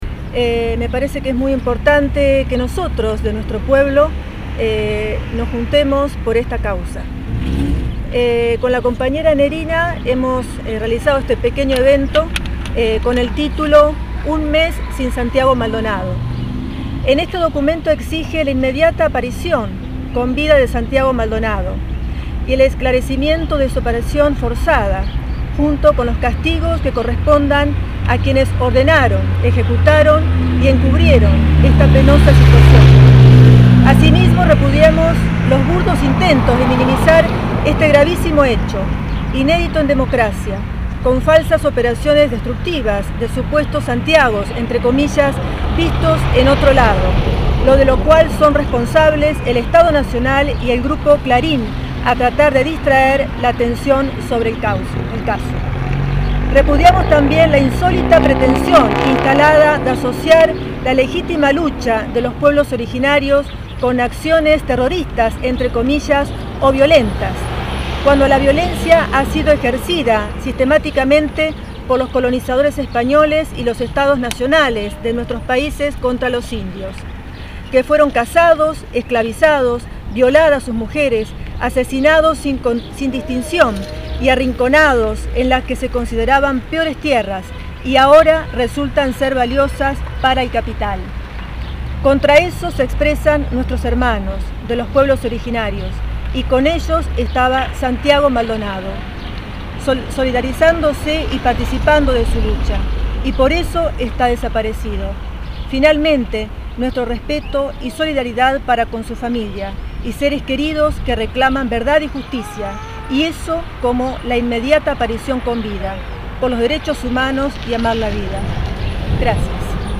Pasadas las 18 se realizó un acto en pedido de la aparición con vida de Santiago Maldonado en Eduardo Castex, fue frente al Cucú en la plaza San Martín.
La convocatoria la realizó la concejal del Frente Castense Marina Baigorria y estuvo acompañada por la legisladora local Claudia Tamagnone y dos personas más, la comunidad castense no se hizo eco de este acto como sucedió en gran parte del país.